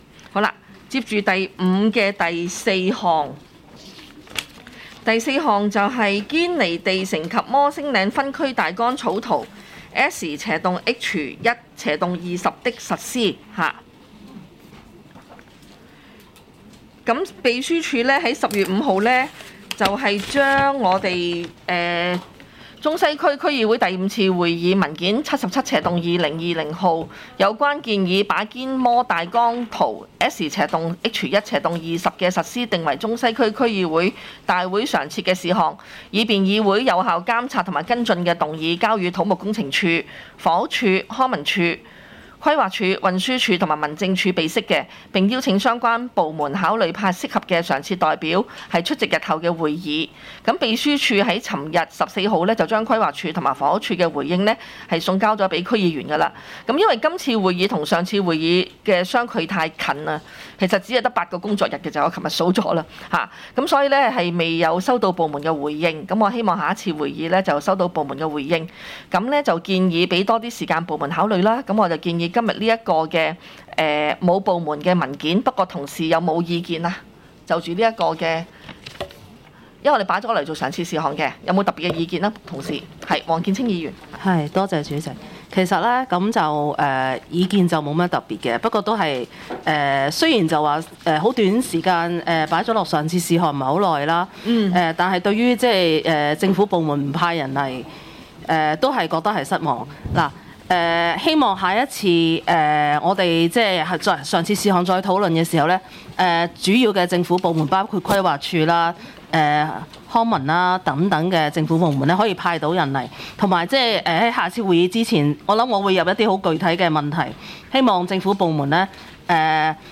區議會大會的錄音記錄
中西區區議會第六次會議